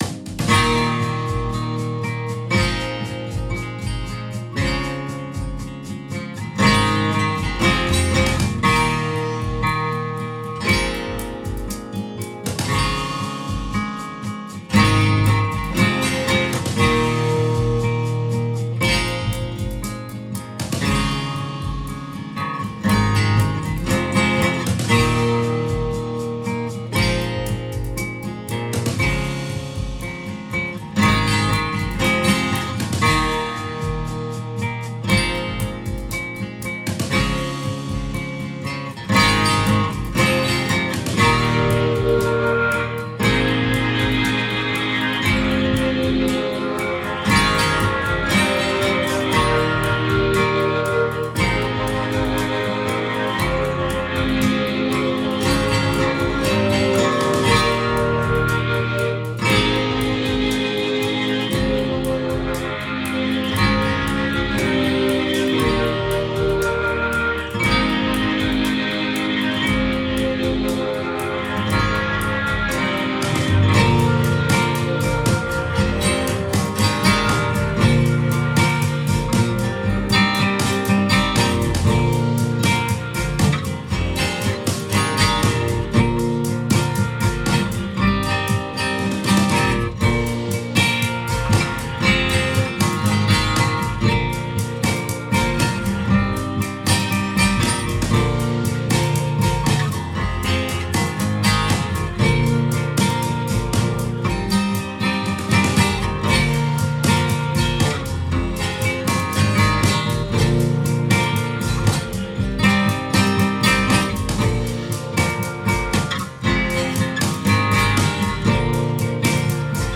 Genre: Rock, Jam Band, Americana, Roots.